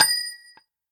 nut_impact_07.ogg